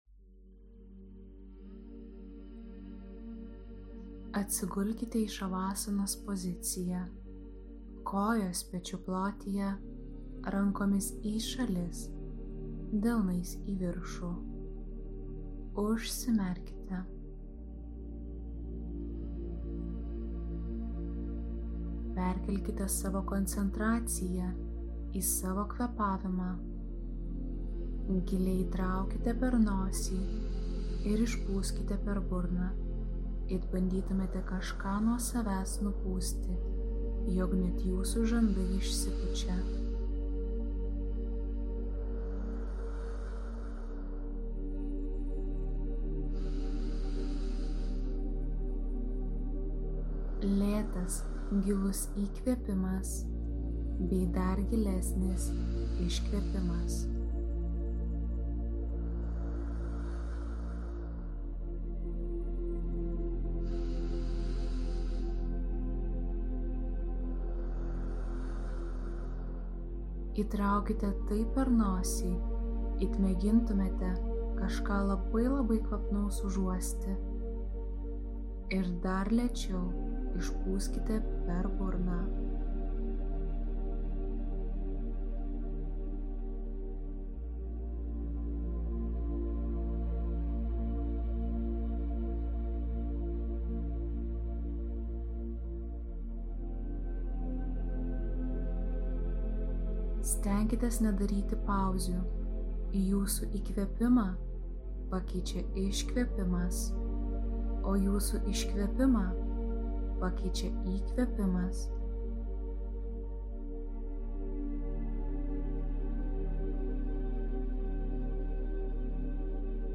Ši kvėpavimo meditacija skirta atkurti ryšį su mama (gimdytoja). Tai itin svarbus pirmas žingsnis.